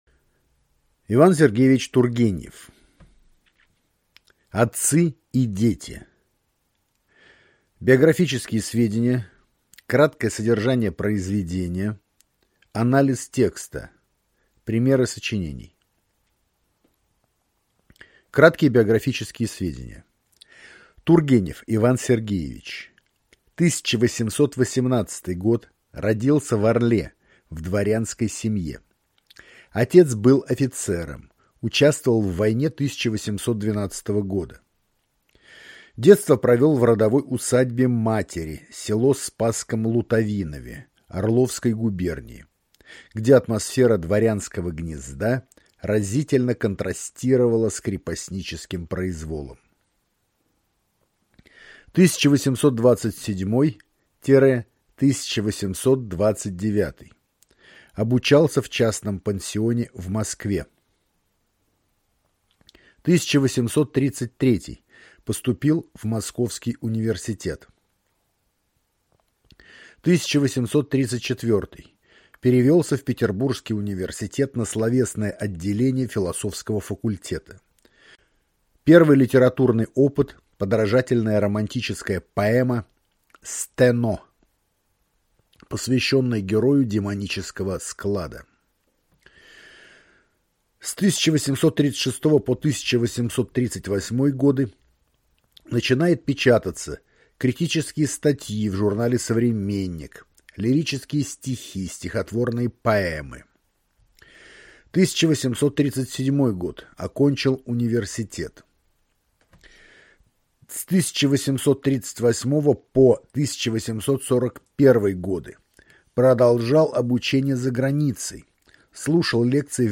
Аудиокнига И. С. Тургенев «Отцы и дети». Краткое содержание.